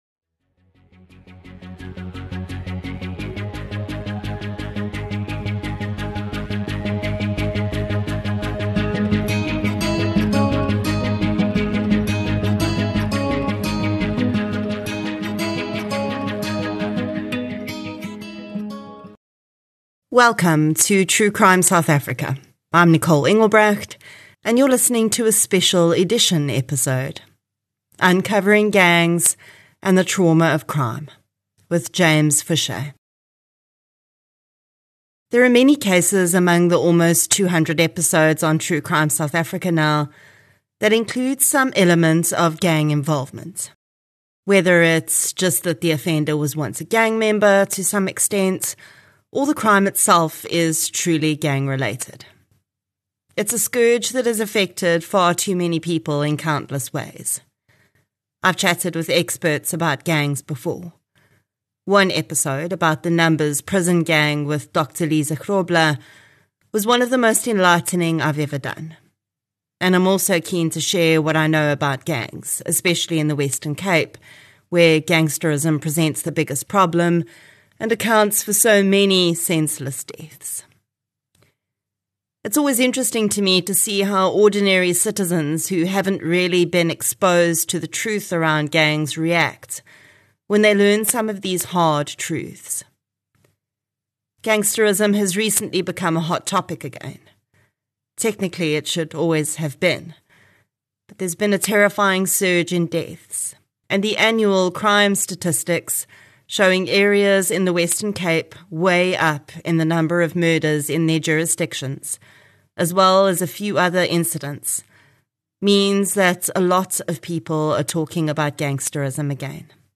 Discussion